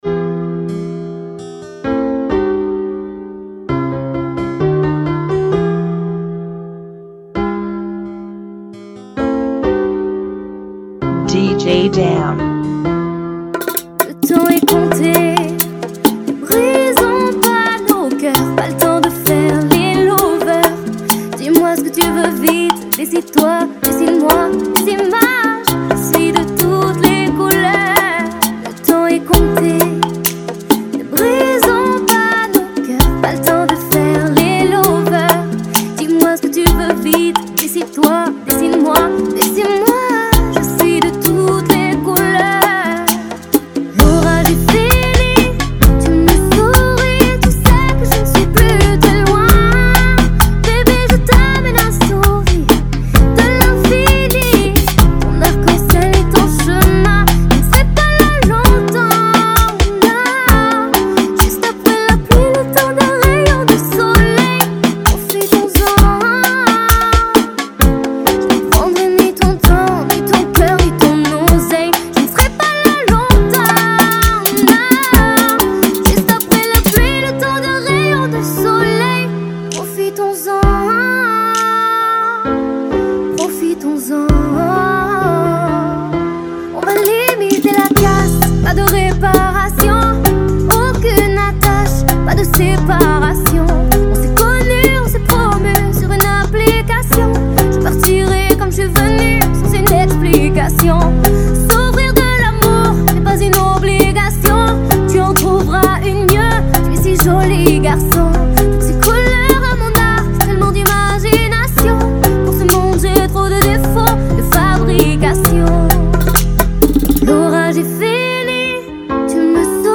131 BPM
Genre: Bachata Remix